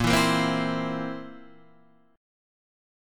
A#sus2b5 chord {x 1 2 3 1 0} chord